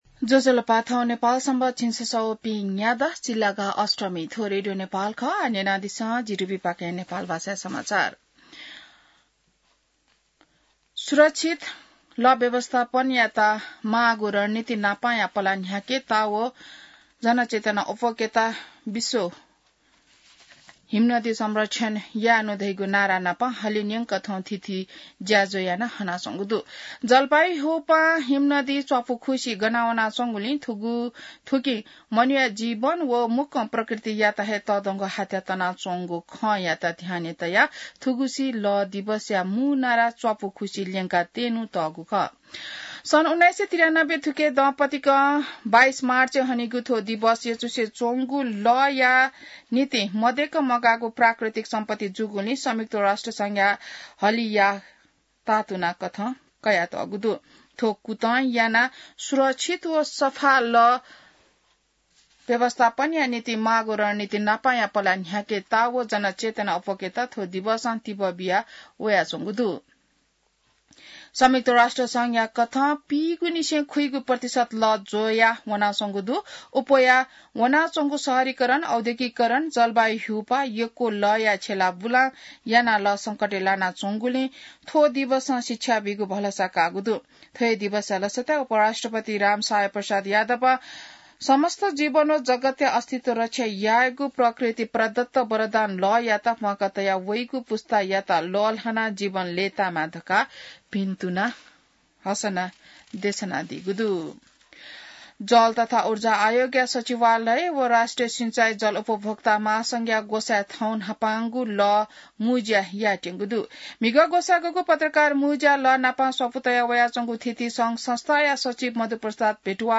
नेपाल भाषामा समाचार : ९ चैत , २०८१